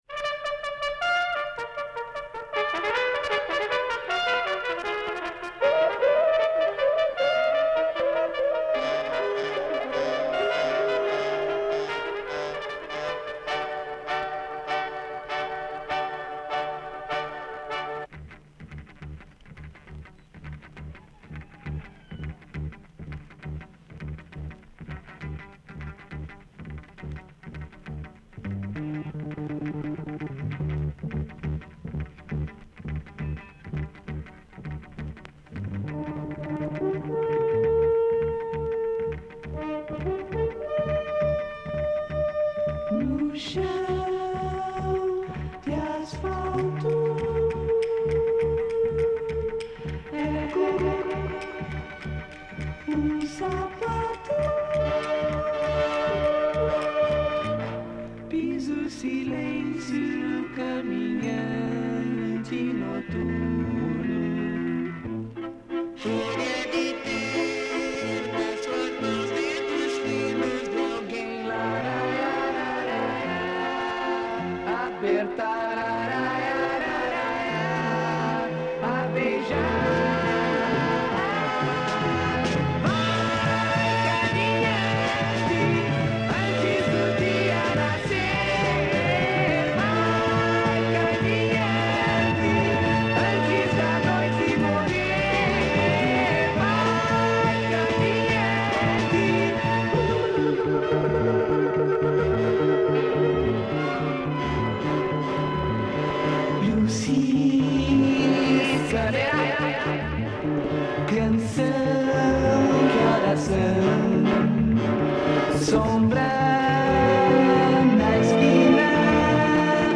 je vous ai fait un rip de la face A